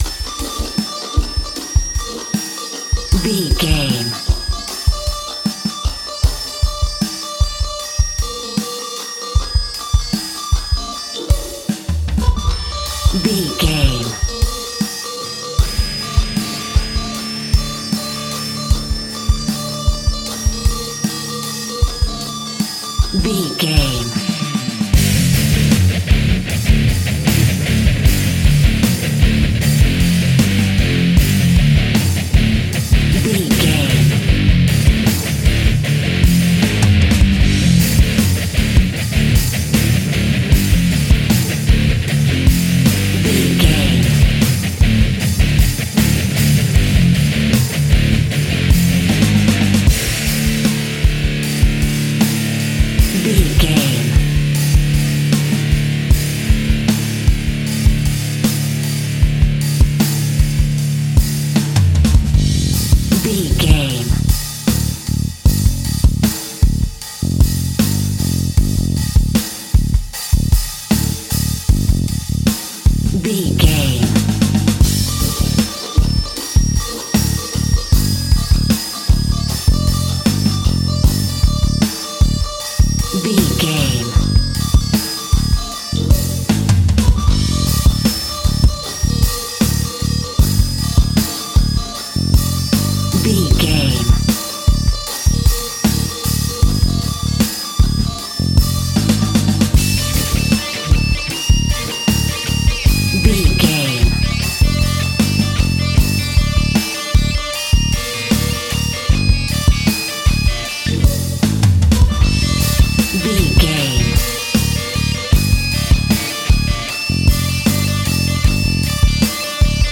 Powerful Rock Metal Death Full Mix.
Epic / Action
Fast paced
Aeolian/Minor
heavy metal
instrumentals
Rock Bass
heavy drums
distorted guitars
hammond organ